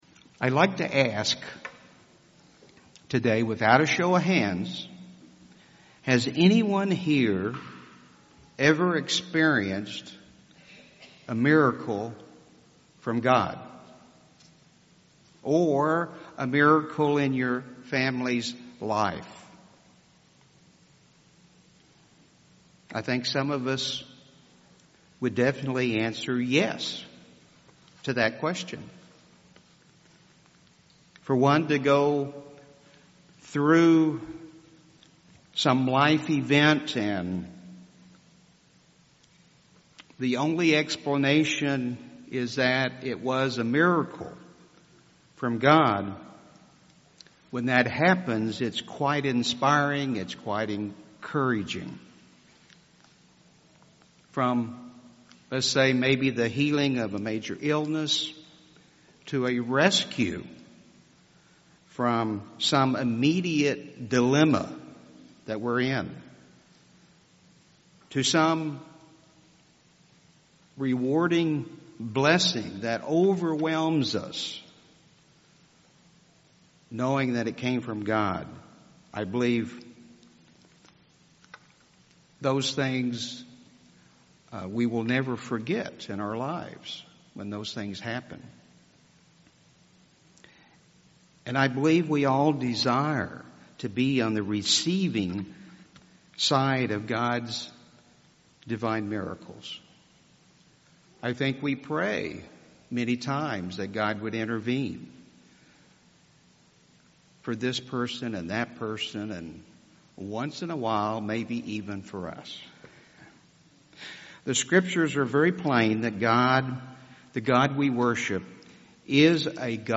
Sermons
Given in Tulsa, OK